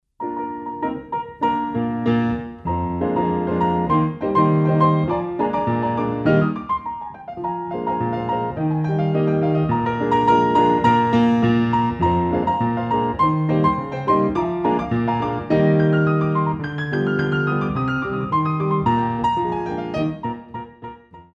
Coda